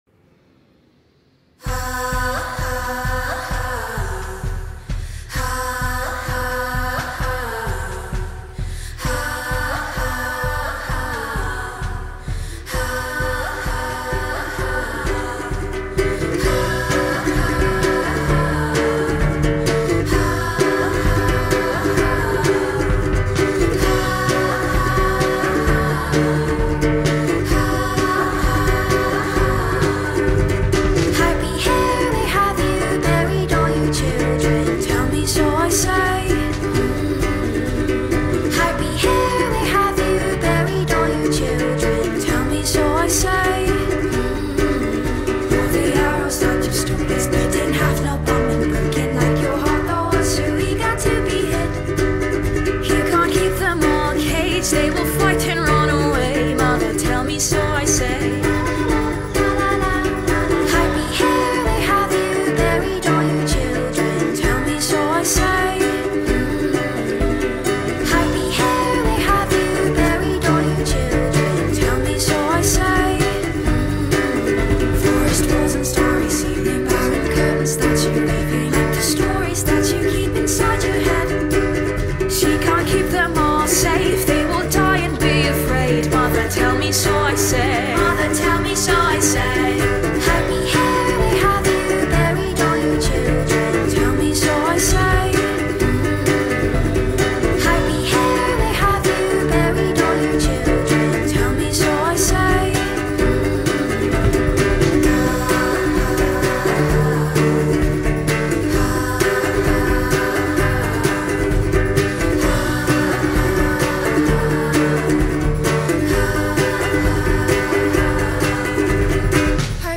Alternative / Indie Electronic